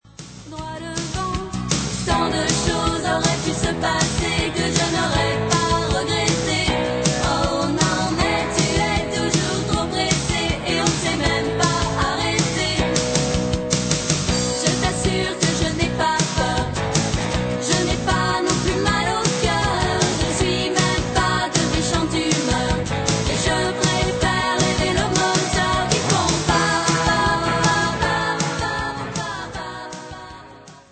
guitare, chant
basse, chant
batterie, elles jouaient un rock sixties